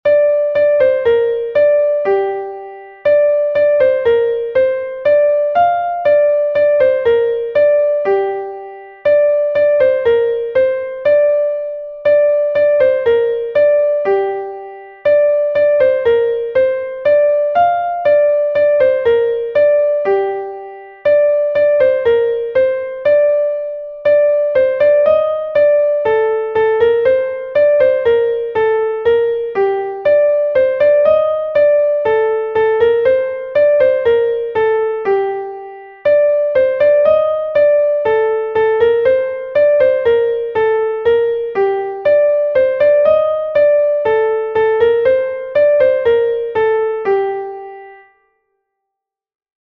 Cheleuet Tud Yaouank est un Hanter dro de Bretagne enregistré 1 fois par Kistinidiz
Hanter dro